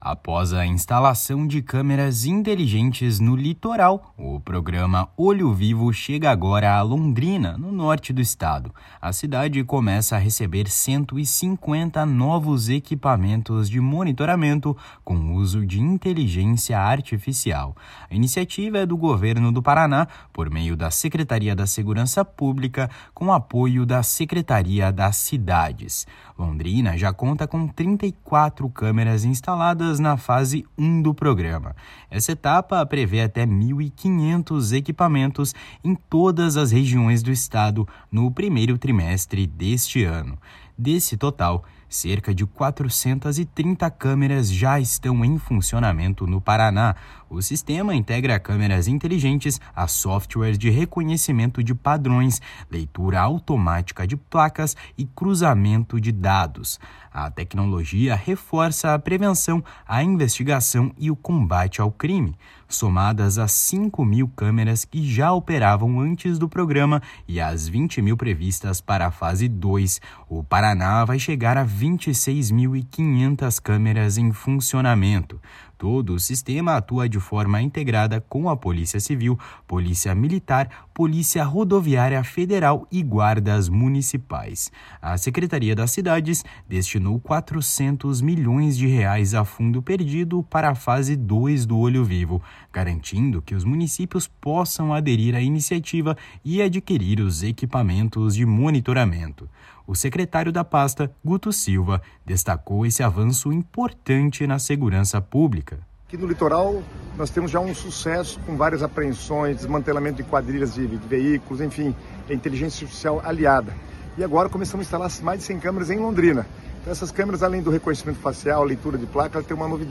O secretário estadual das Cidades, Guto Silva, pasta que recentemente disponibilizou R$ 400 milhões a fundo perdido para a fase 2 do programa para que os municípios possam aderir ao Olho Vivo e adquirir os equipamentos, falou sobre este avanço importante na segurança pública.